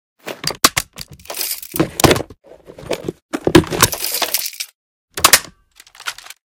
0335ec69c6 Divergent / mods / M249 Reanimation / gamedata / sounds / weapons / librarian_m249 / reload.ogg 46 KiB (Stored with Git LFS) Raw History
reload.ogg